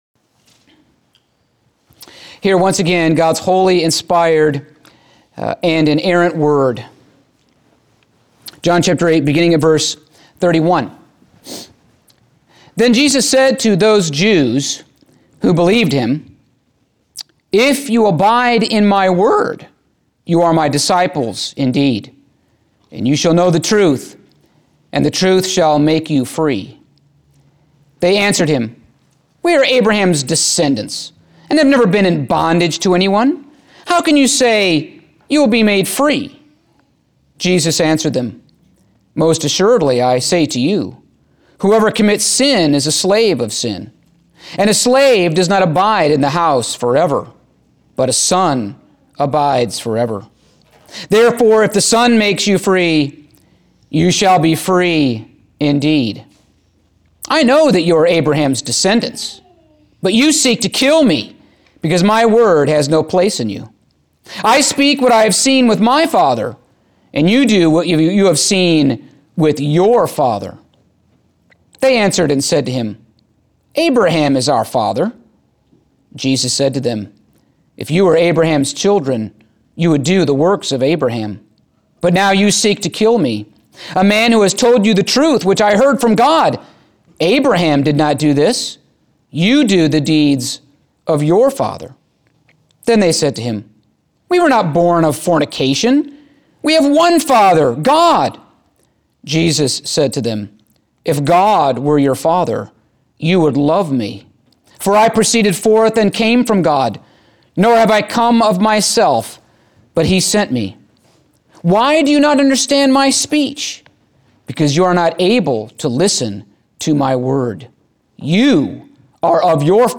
Passage: John 8:37-47 Service Type: Sunday Morning